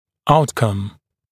[‘autkʌm][‘ауткам]результат, исход, итог